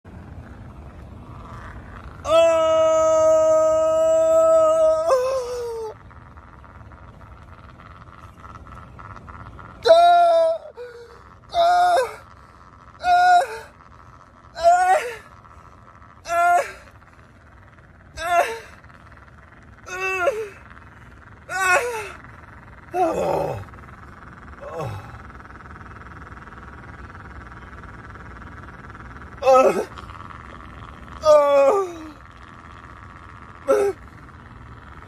Crying In Pain